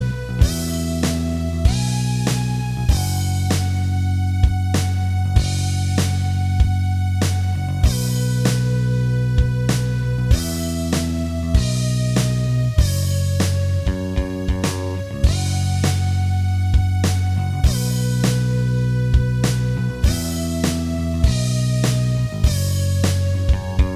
Minus Guitars Rock 4:57 Buy £1.50